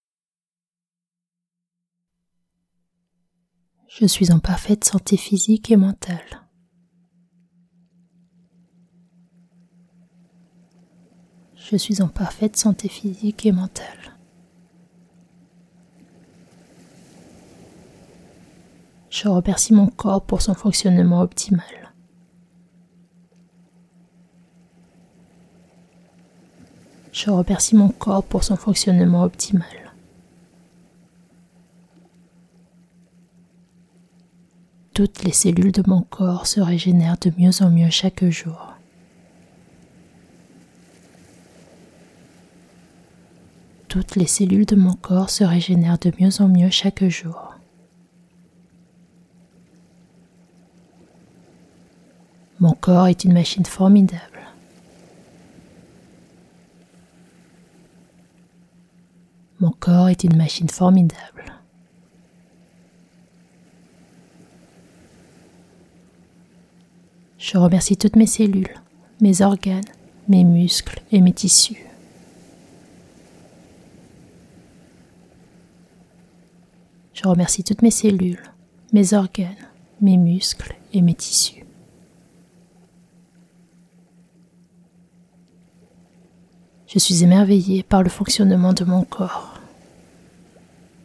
Séance d’1h d’affirmations positives pour une santé physique et mentale optimale.